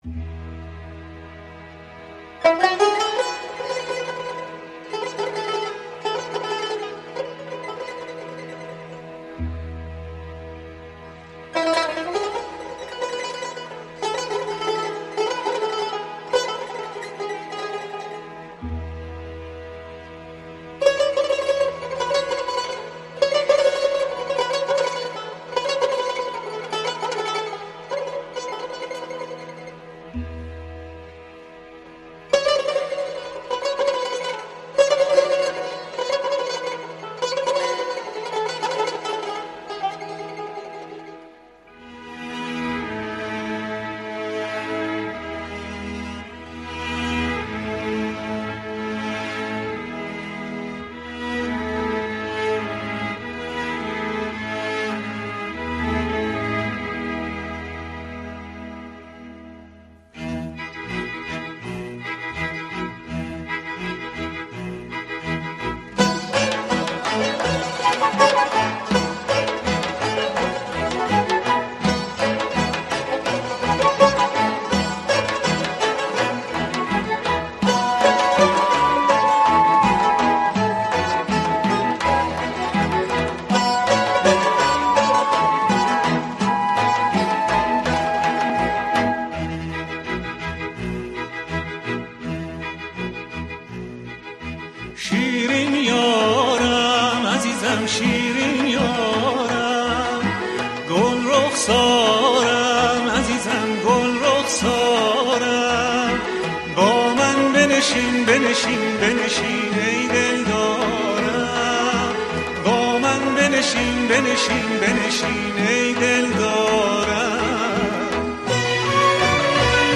بر اساس ملودی محلی آذربایجان